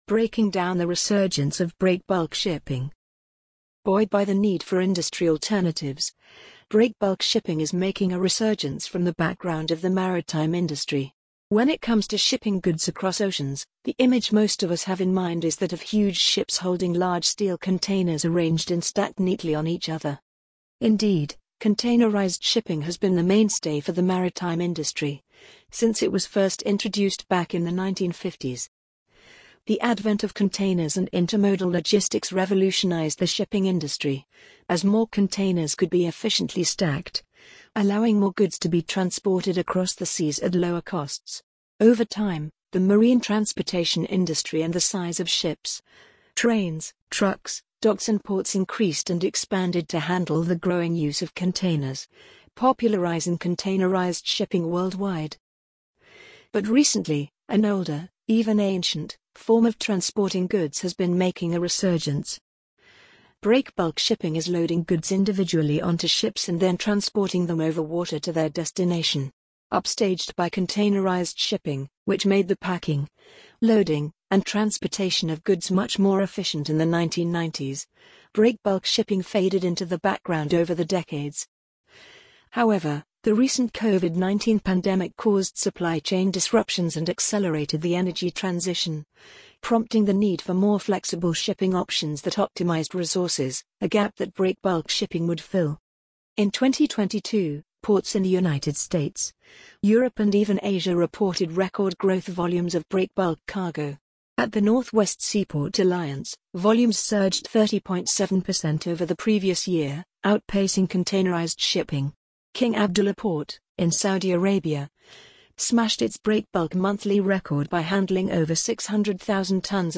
amazon_polly_46131.mp3